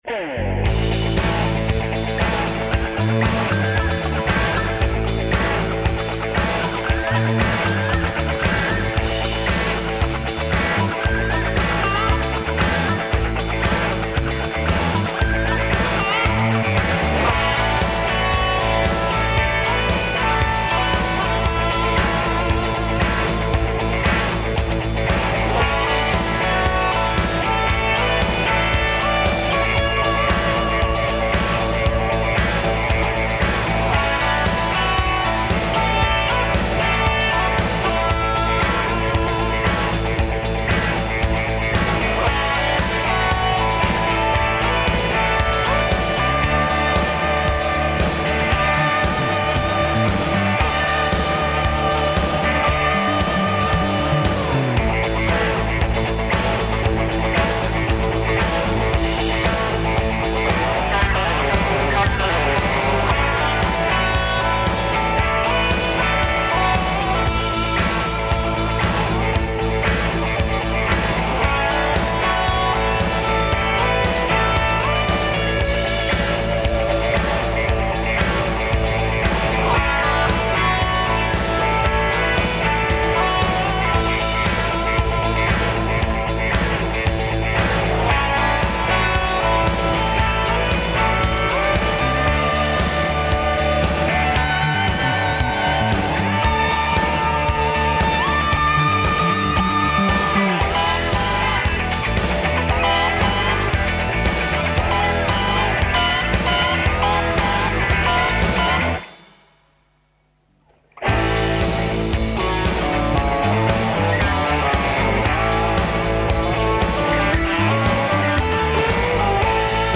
Наши гости долазе, или ће се телефоном укључити из свих места која су обухваћена пројектом, а чућемо и представнике Министарства културе и Завода за заштиту споменика. Шта морамо научити о својој прошлости да би је претворили у ресурс?